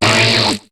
Cri de Ninjask dans Pokémon HOME.